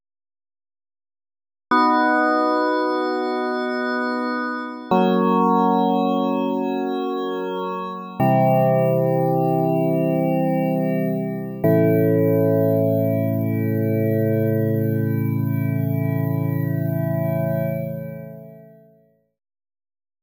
This is a collection of 3-voice single-cycle chord waves created with Just intonation.
Waveforms are bit-crushed and downsampled for effect.
Maj - Organ Wave with Unison